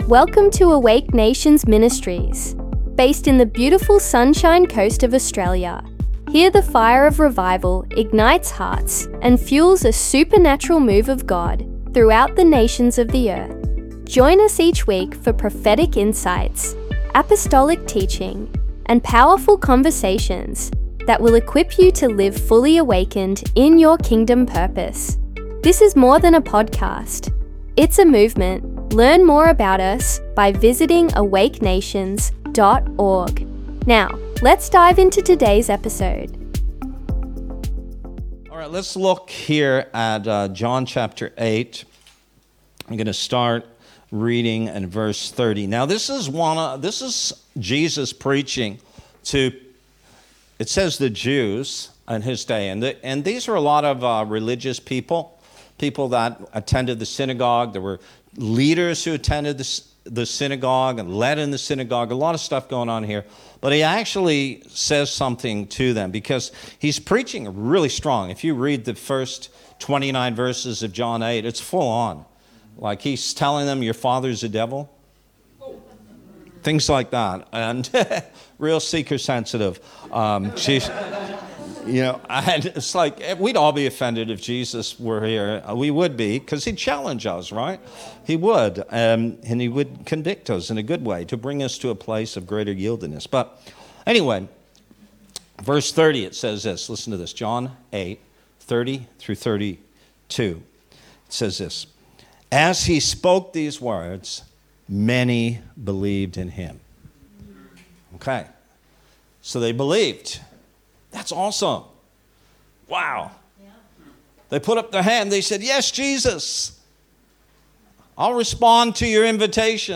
In this powerful teaching from Awake Nations Ministries